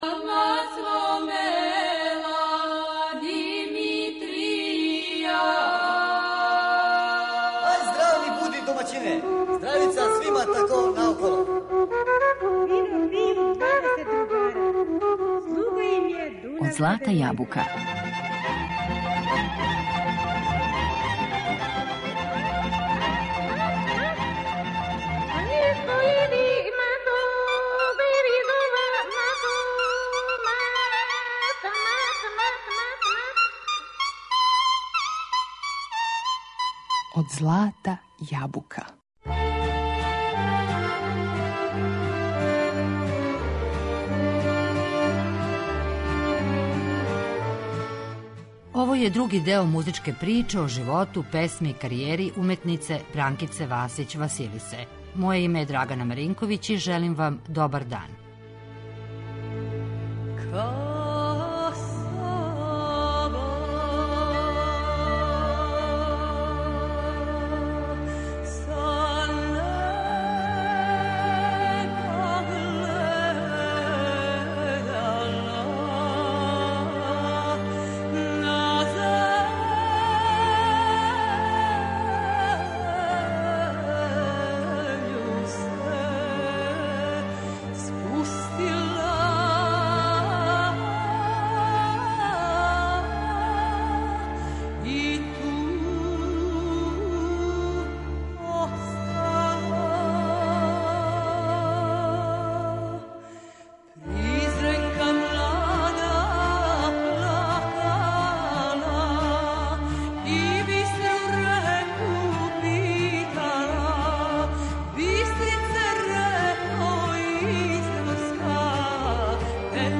Својим вокалом скренула је пажњу јавности изводећи старе, заборављене песме.